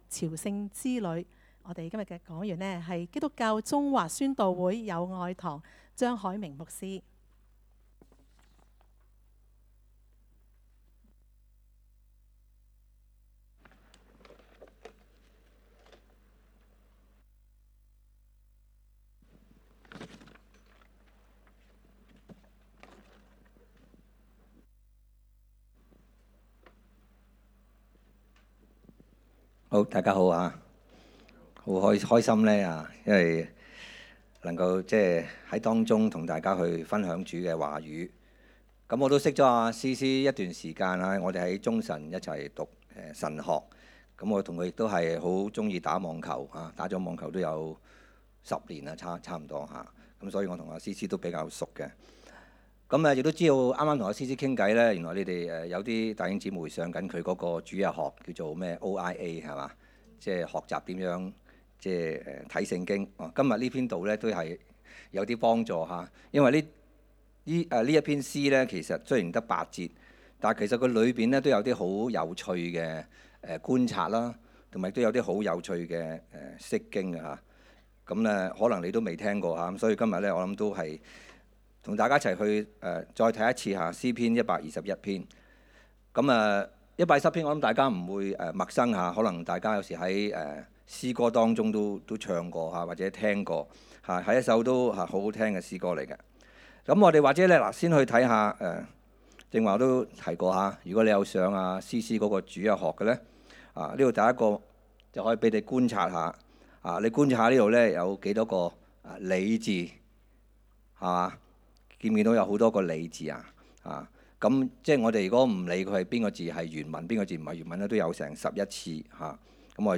講道 ： 朝聖之旅